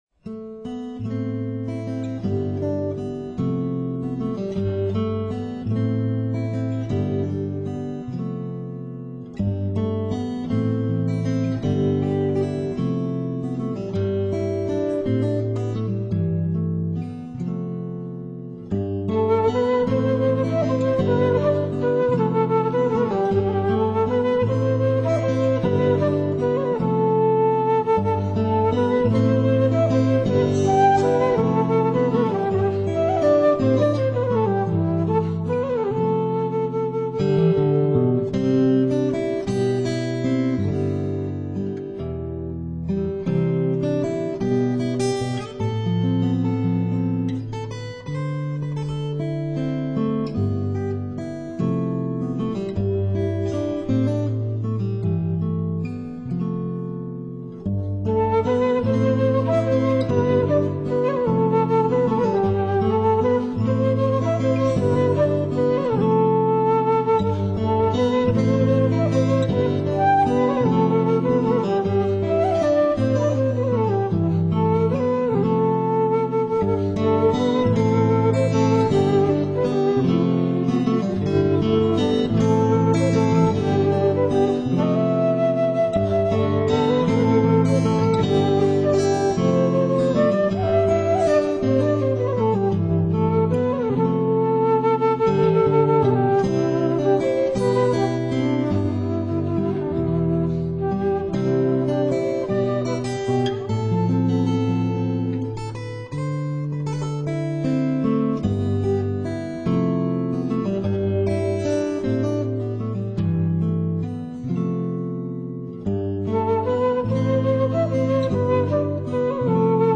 lungo le rotte della musica tradizionale celtica, tra Atlantico e Mediterraneo
Pretty Girl Milking a Cow (air) - un'antica aria, molto più struggente di quanto non suggerisca il titolo, cui il flauto in alabastro restituisce tutto il fascino arcaico
Pretty Girl Milking a Cow (air) 2.30